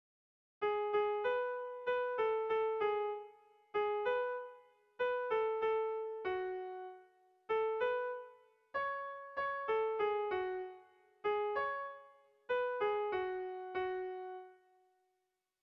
Air de bertsos - Voir fiche   Pour savoir plus sur cette section
Irrizkoa
AB